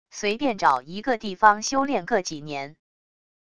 随便找一个地方修炼个几年wav音频生成系统WAV Audio Player